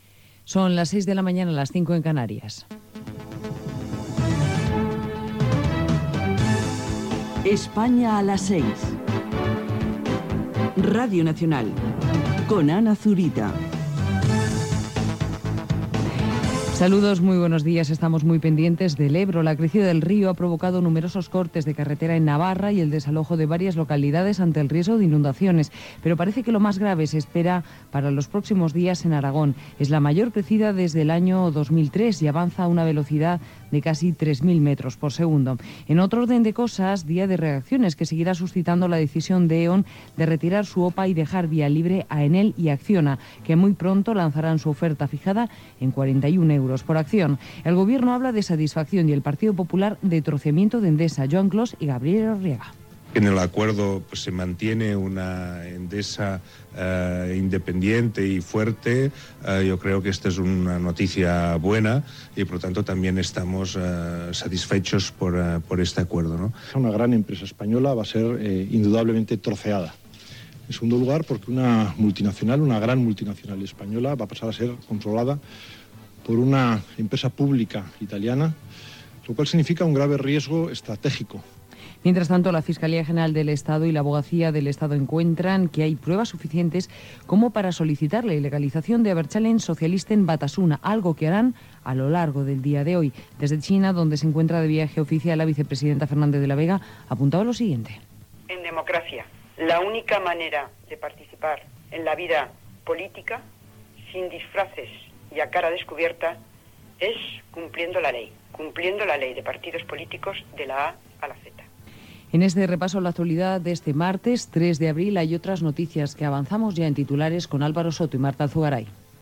Hora, careta del programa, la crescuda del cabal del riu Ebre, ENDESA (declaracions de Joan Clos), il·legalització d'un partit abertzale basc
Informatiu